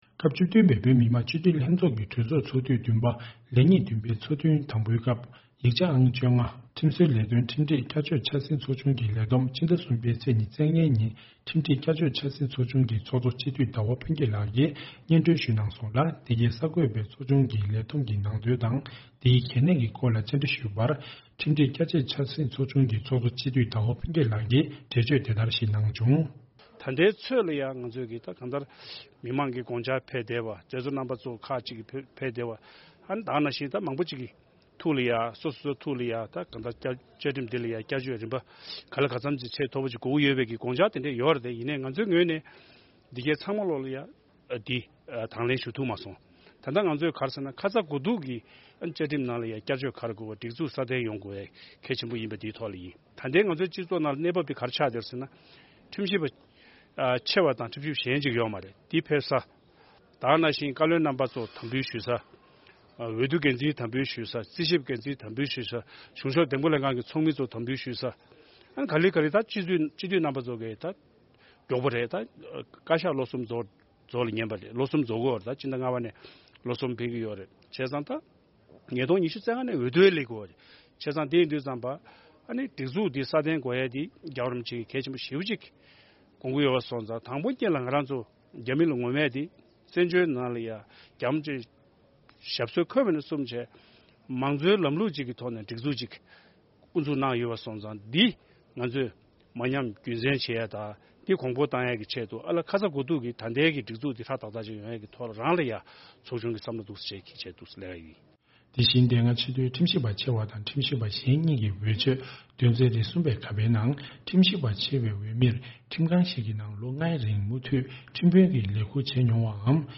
སྤྱི་འཐུས་ལྷན་ཚོགས་ཀྱི་གྲོས་ཚོགས་ཚོགས་དུས་བདུན་པའི་སྐབས་ཡིག་ཆ་ཨང་ ༡༥ ཁྲིམས་བཟོ་ལས་དོན་ཁྲིམས་སྒྲིག་བསྐྱར་བཅོས་འཆར་ཟིན་ཚོགས་ཆུང་གི་ལས་བསྡོམས་དེ་སྤྱི་ཟླ་ ༣ ཚེས་ ༢༥ ཉིན་ཁྲིམས་སྒྲིག་བསྐྱར་བཅོས་འཆར་ཟིན་ཚོགས་ཆུང་གི་ཚོགས་གཙོ་སྤྱི་འཐུས་ཟླ་བ་ཕུན་སྐྱིད་ལགས་ཀྱིས་སྙན་སྒྲོན་ཞུས་གནང་།